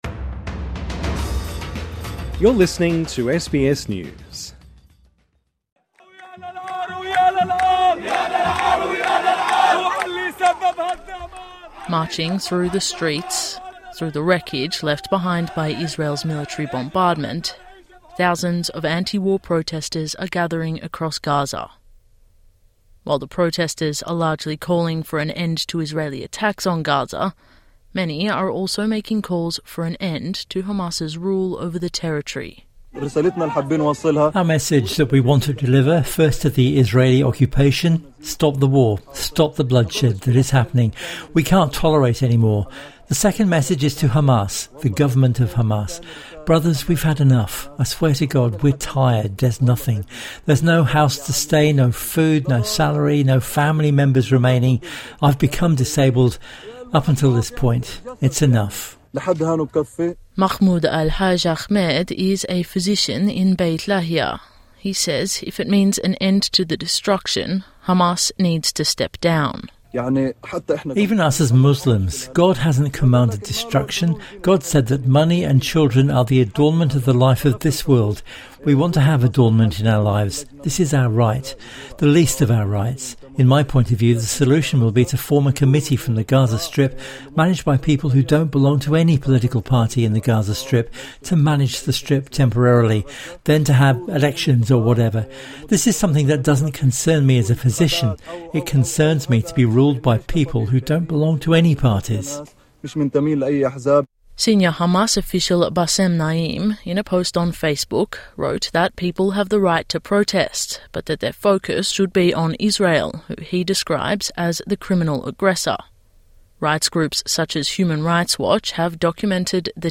SBS News In Depth